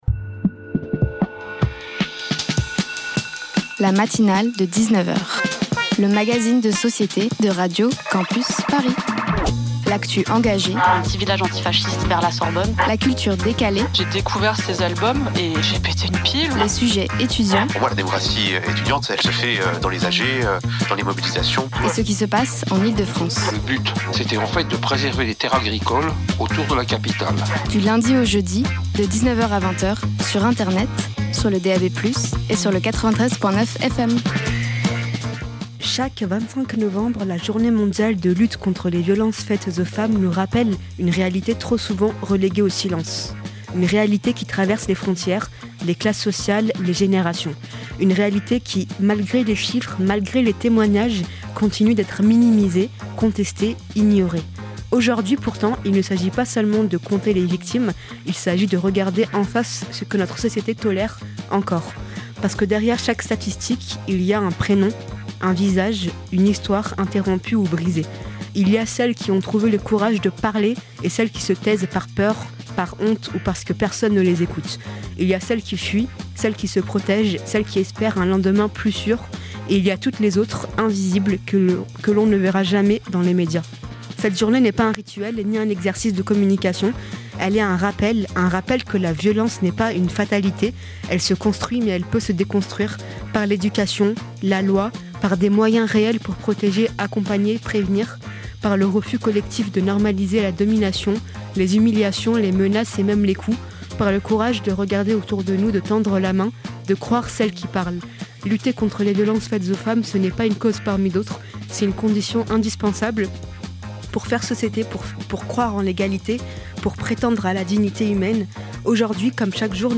Semaine de la réduction des déchets & santé mentale des jeunes Partager Type Magazine Société Culture mardi 25 novembre 2025 Lire Pause Télécharger Ce soir